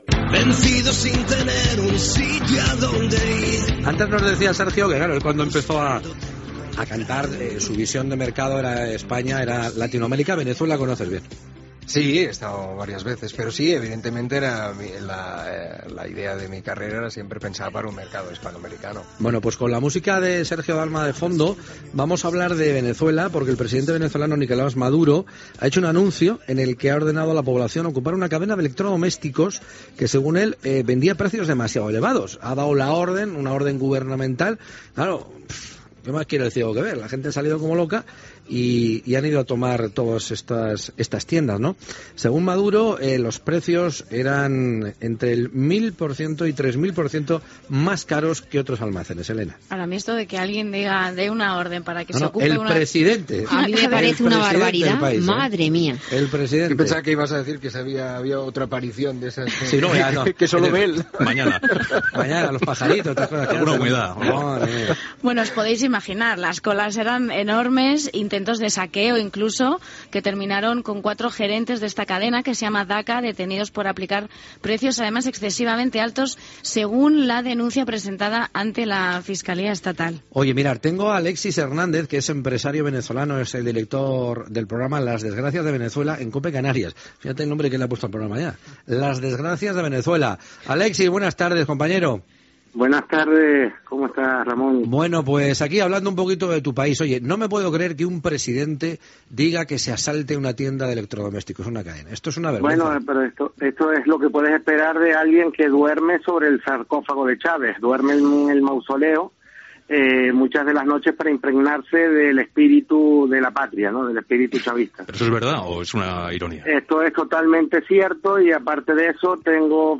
Fragment d'una entrevista al cantant Sergio Dalma (Josep Capdevila).
Entreteniment
FM